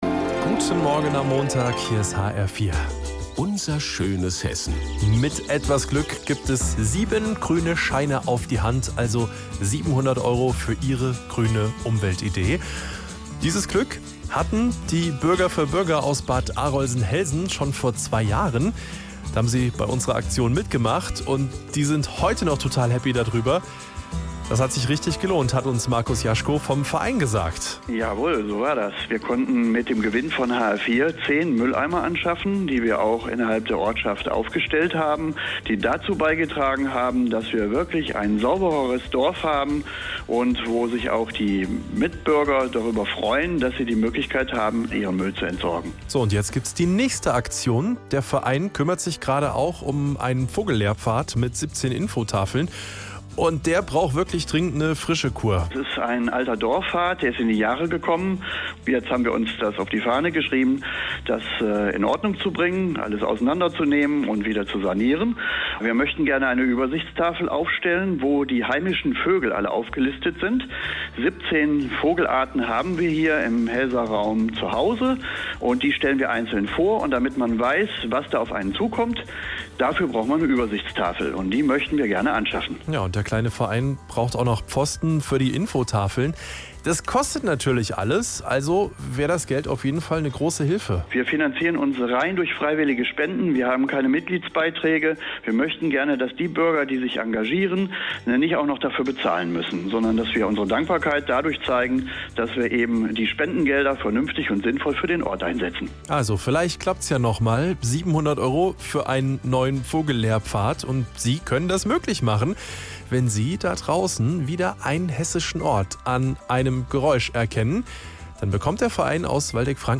Am Dienstag, 22.04. wurde ich interviewt. Ich stellte unsere Planungen zur alten Vogelfahrt und künftigen Vogel- und Naturlehrpfad vor.